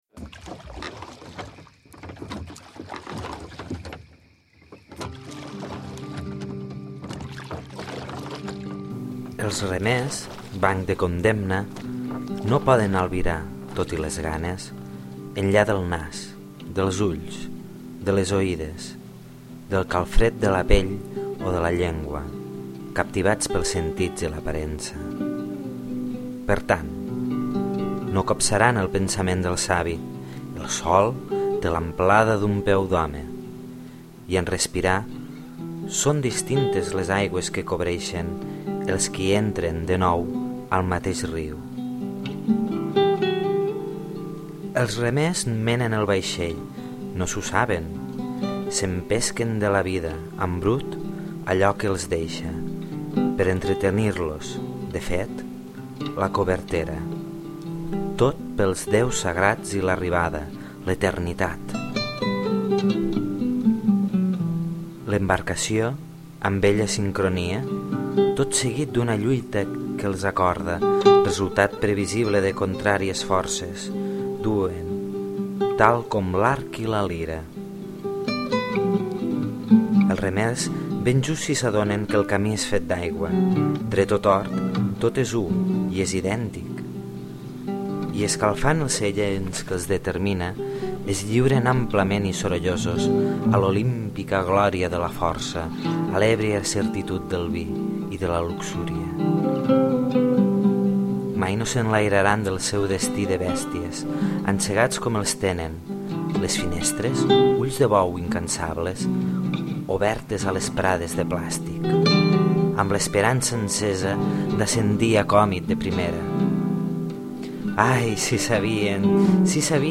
La música és de Frozen Silence.